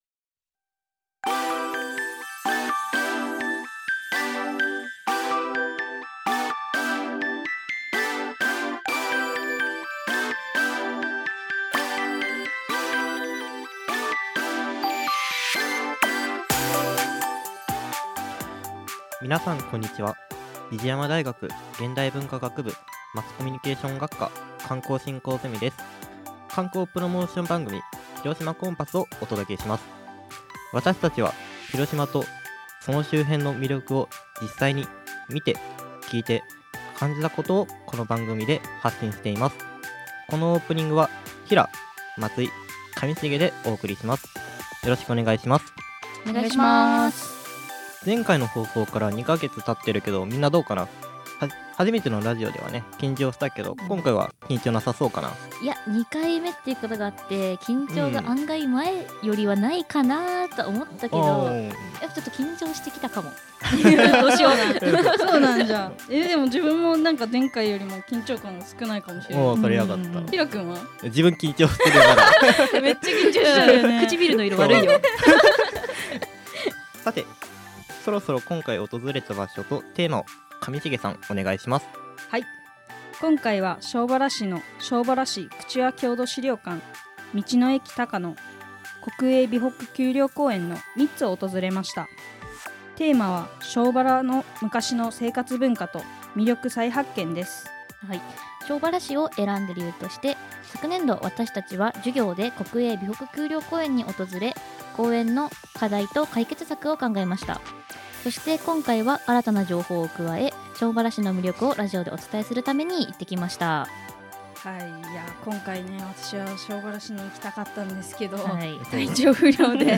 比治山大学presents 観光プロモーションラジオ番組「広島コンパス2025」